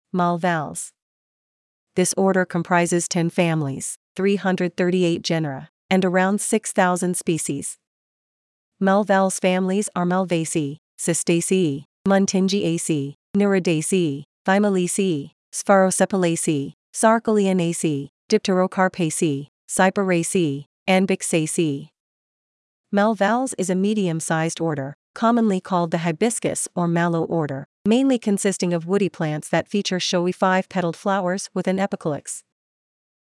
Malvales Pronunciation
Malvales-Pronunciation.mp3